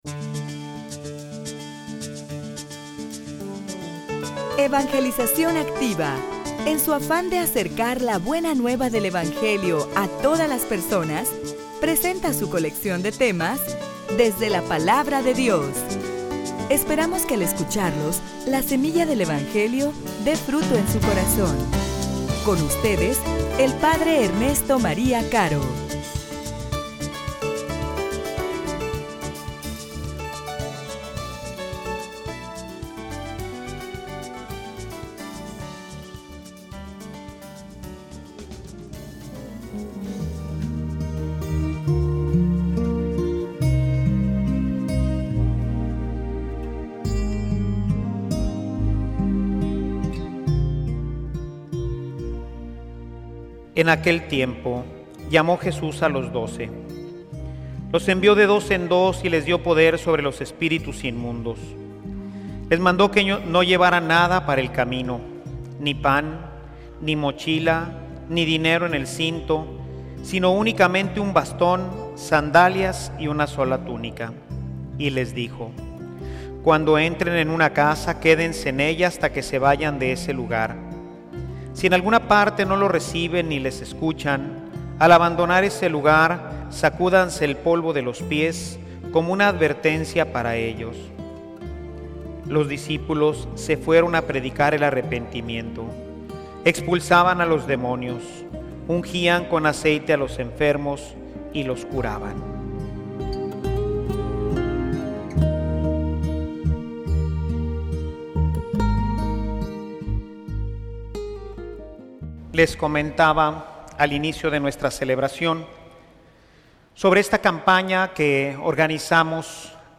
homilia_Sembrador_del_Evangelio_2009.mp3